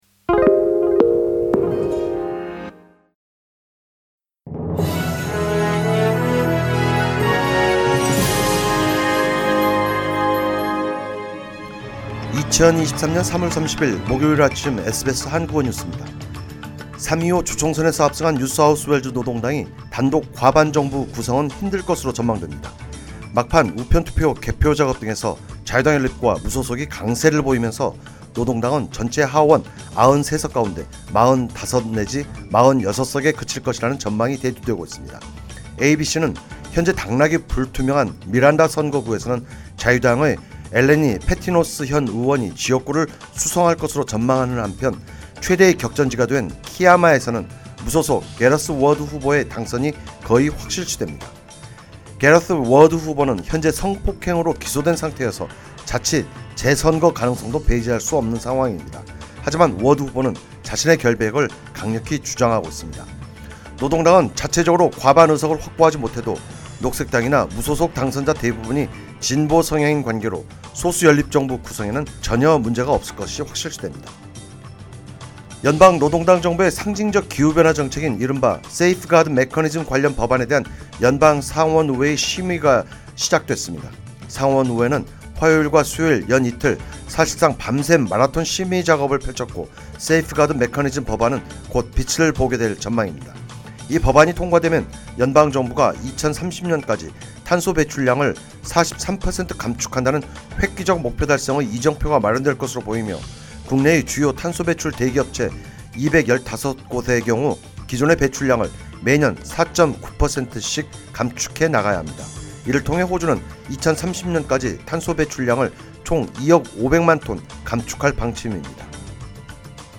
SBS 한국어 아침 뉴스: 2023년 3월 30일 목요일
2023년 3월 30일 목요일 아침 SBS 한국어 뉴스입니다.